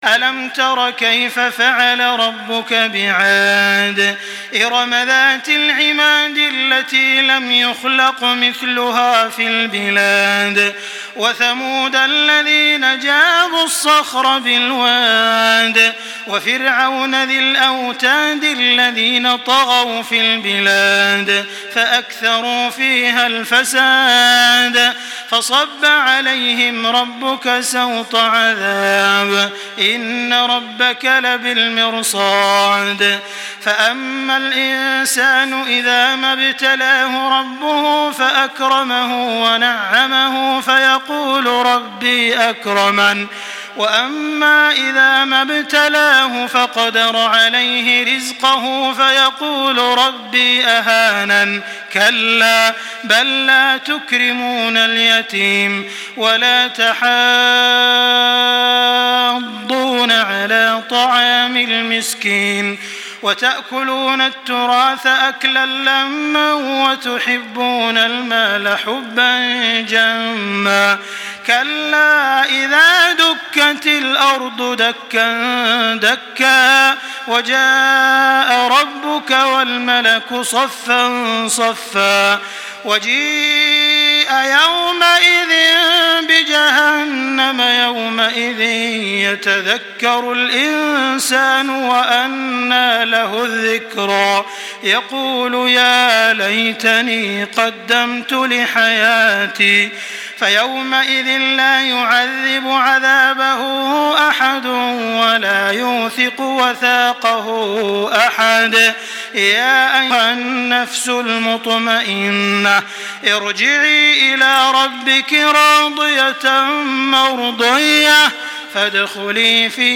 Makkah Taraweeh 1425
Murattal